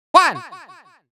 countIn1Farthest.wav